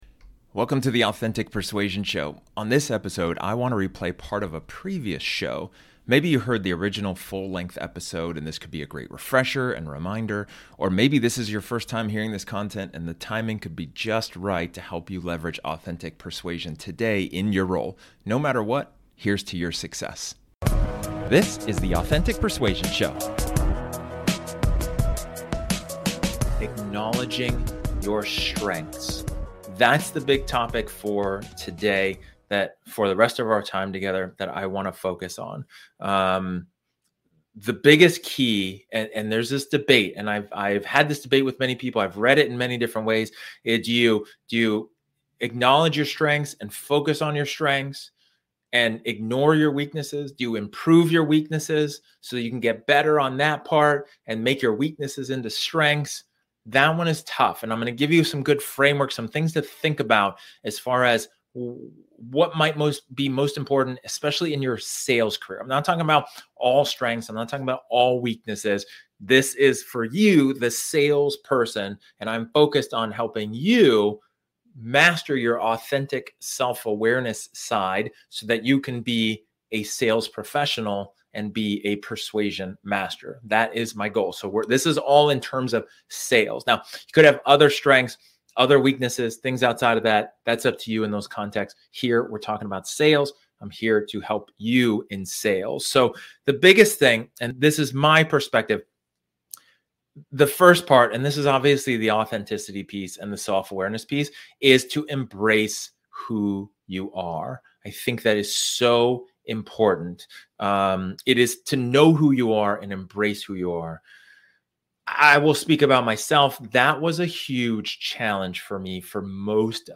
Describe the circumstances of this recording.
In this episode, This episode is an excerpt from one of my training sessions where I talk about the importance of acknowledging your strengths.